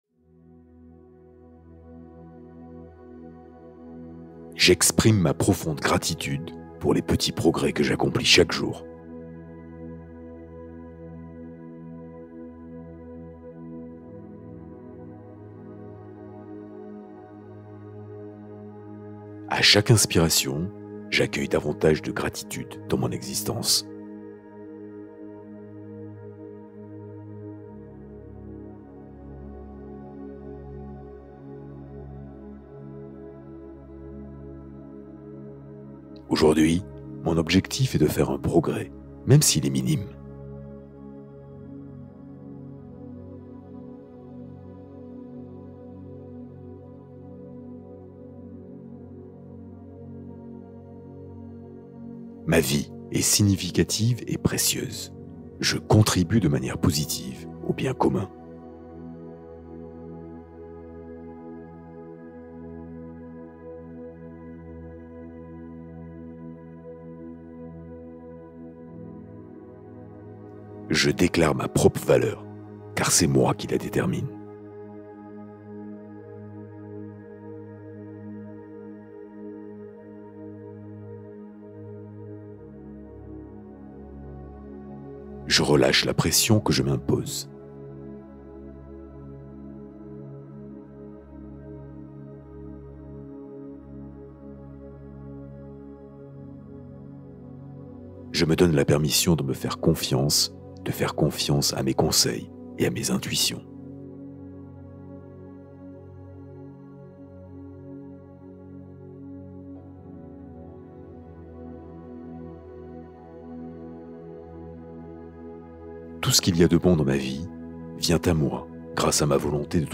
Méditation guidée pour dormir : installer un sommeil lent et réparateur